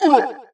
cutVoiceA.wav